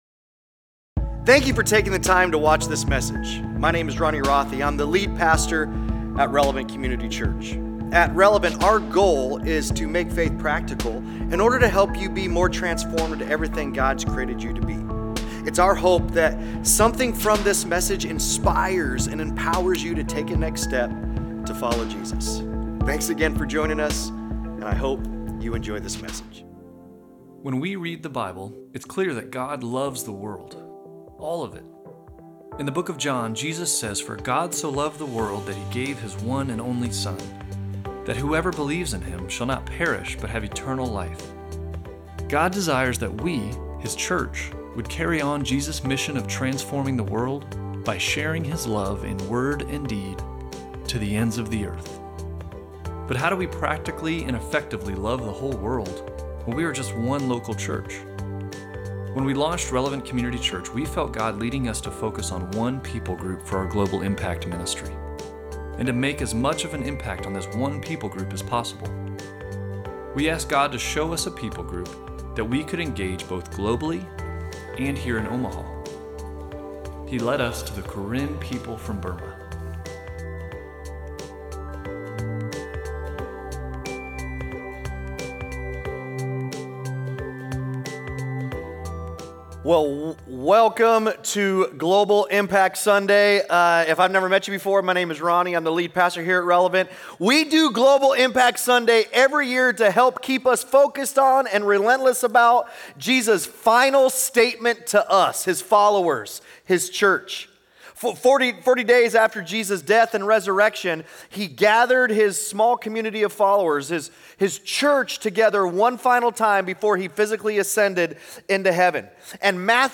Sunday Sermons Global Impact Sunday May 11 2025 | 00:39:57 1x 00:00 / 00:39:57 Subscribe Share Apple Podcasts Spotify Overcast RSS Feed Share Link Embed